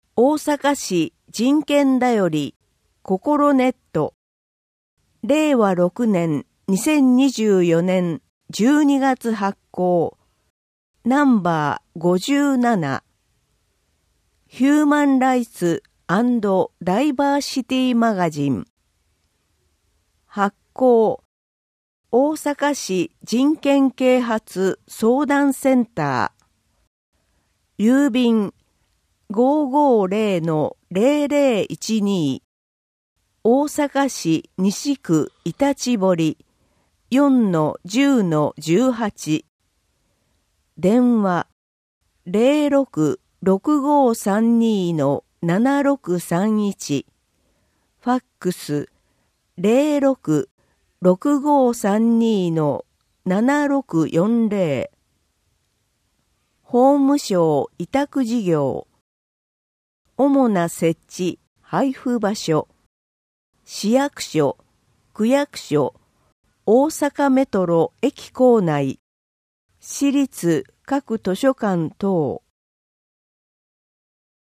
本号につきましては、淀川区で活動されている「音訳ボランティアグループこもれび」の有志の皆様に作成いただきました。